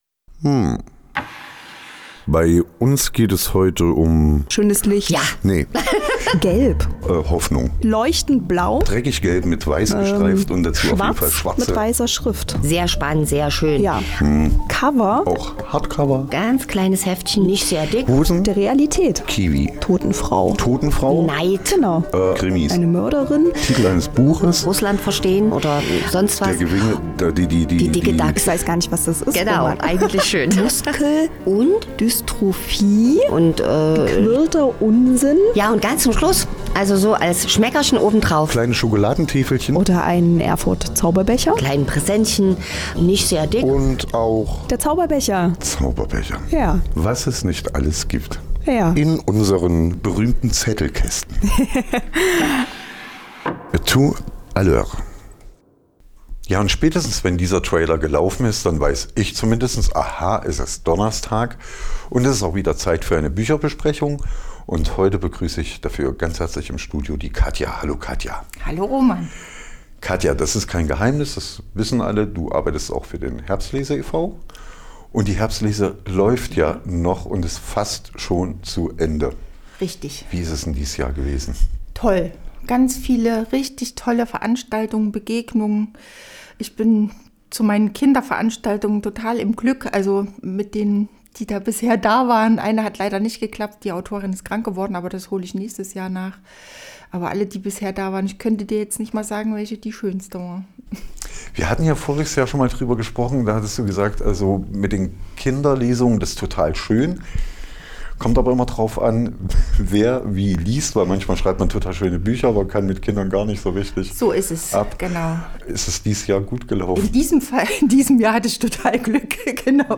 Zettelkasten - die aktuelle Buchbesprechung | Annika und Martin Bosch - Nepomuk und der Rabel